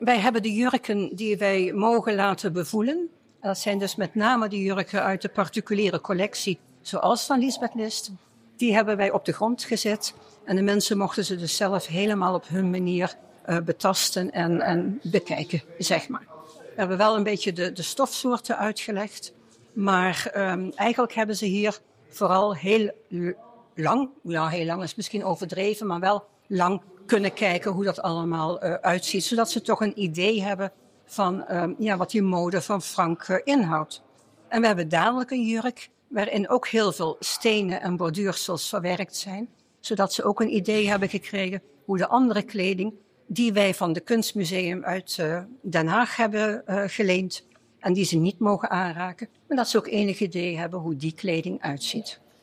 In de reportage ga je naar Stadsmuseum Veenendaal, want daar is op dit moment de modetentoonstelling ‘Frank Govers – The Power of Style’ mee te maken.